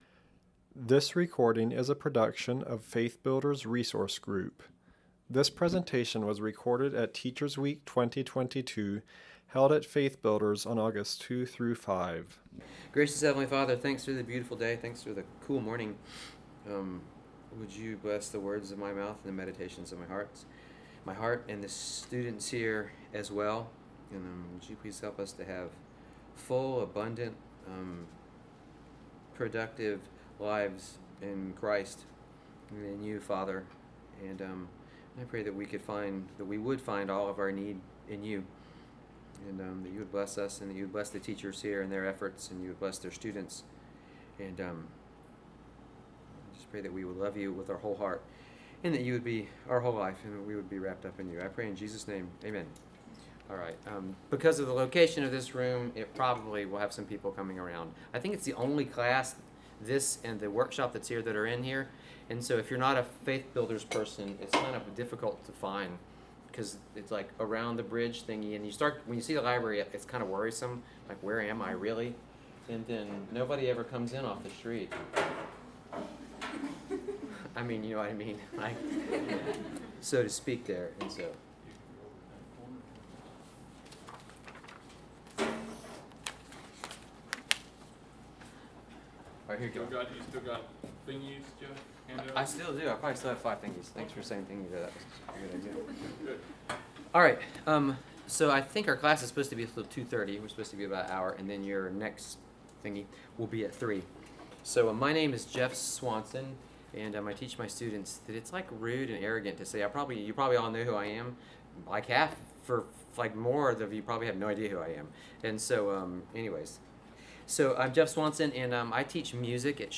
Home » Lectures » Healthy Teacher, Happy teacher